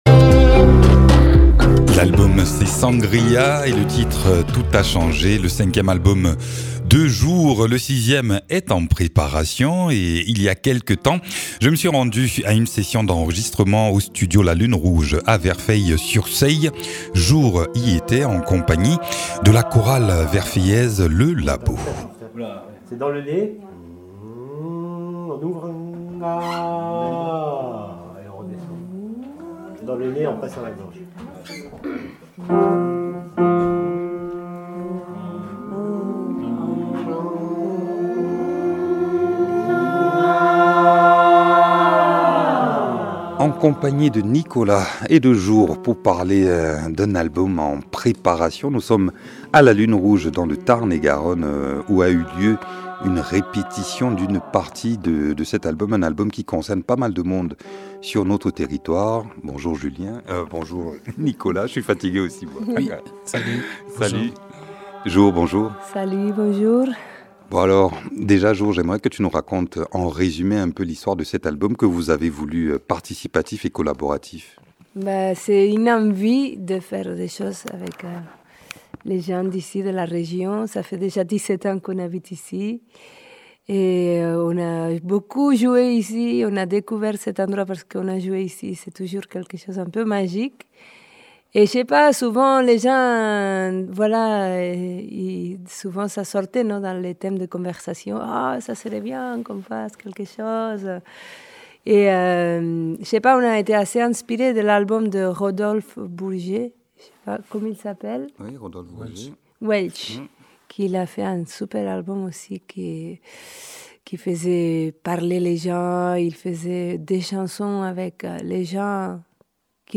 Interviews
Un album en guise de photo du moment de notre pays. Dans ce numéro, un reportage lors d’une session de répétition avec une chorale verfeillaise.